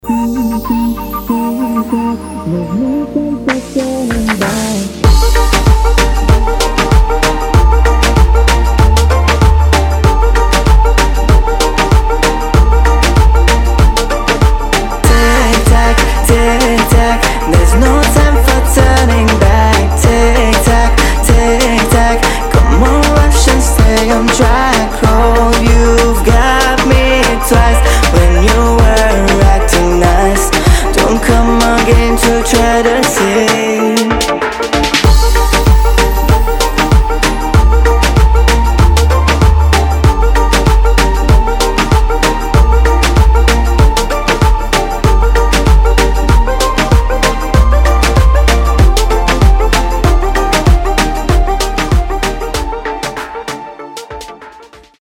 Романтичная и очень теплая мелодия!